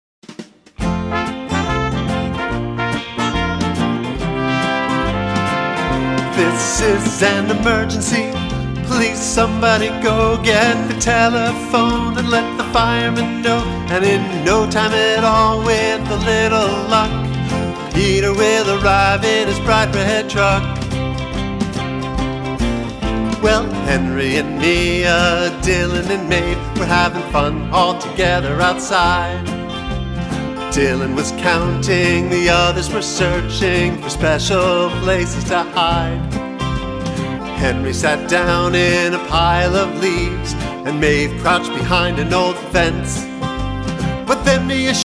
With a youthful, warm and energetic style